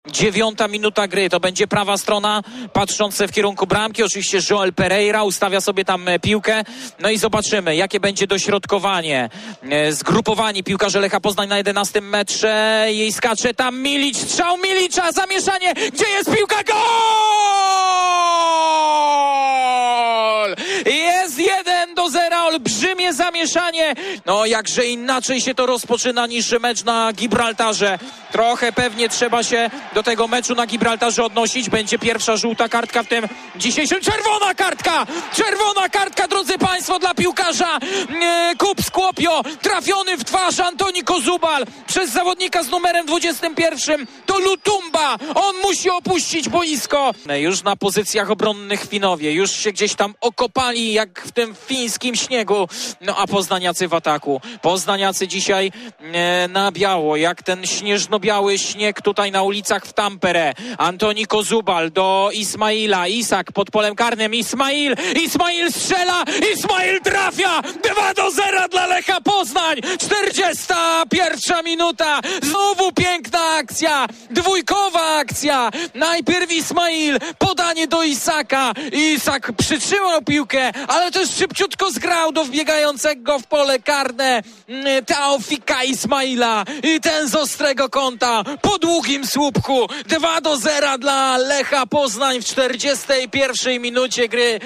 e1x22qr1sp1kngo_gole_tampere.mp3